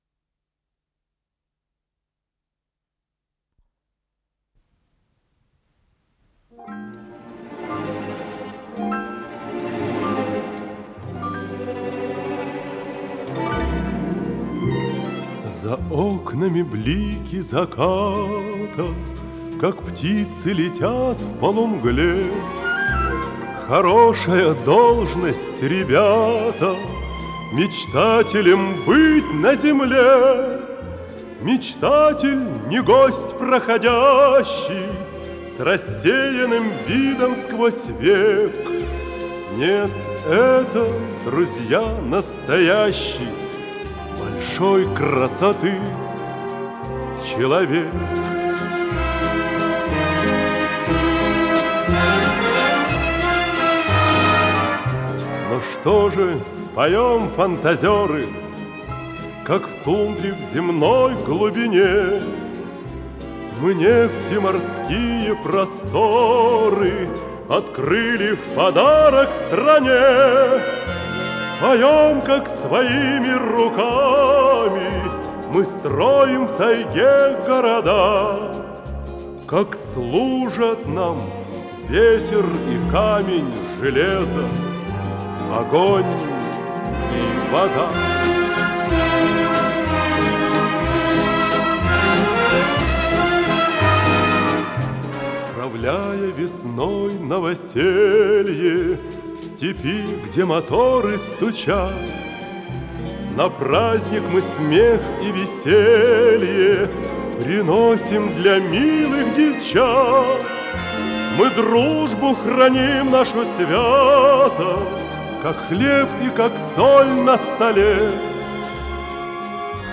с профессиональной магнитной ленты
ПодзаголовокВальс
ВариантДубль моно